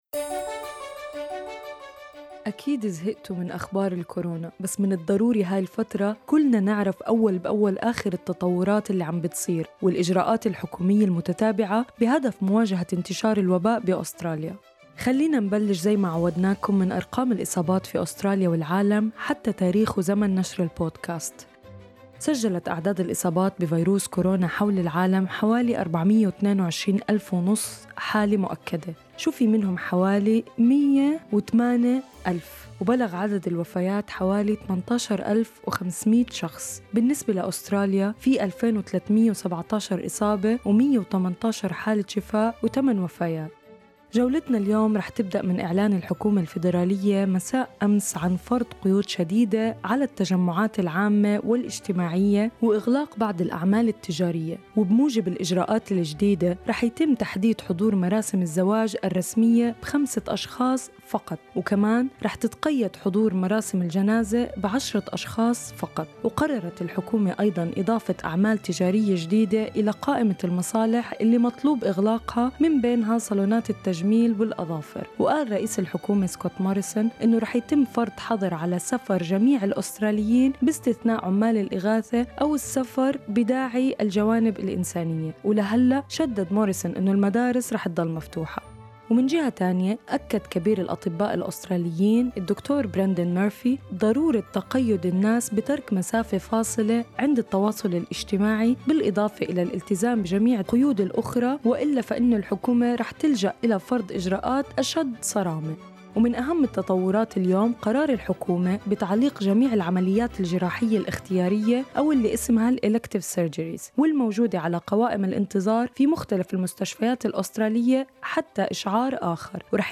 corona_25_with_music_mixdown.mp3